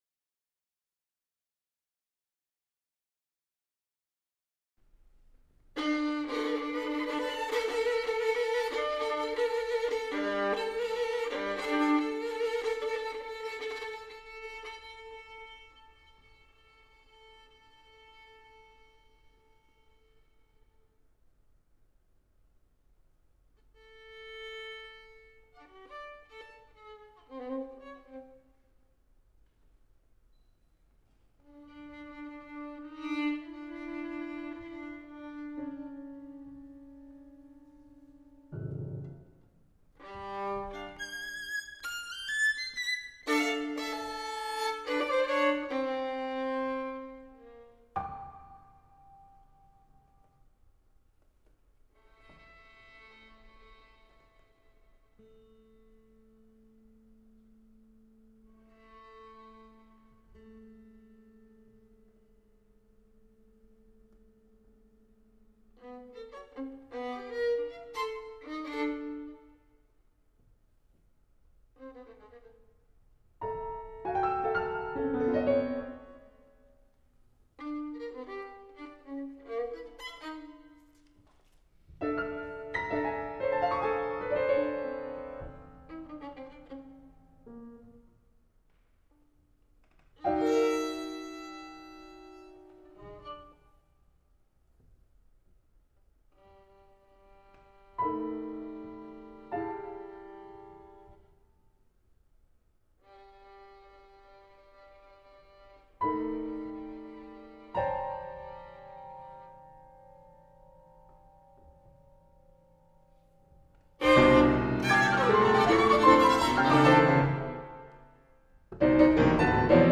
Violin
Piano
St John’s Smith Square London
Live performance – 15th  November 2018